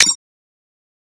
Audio_BNV_click.ogg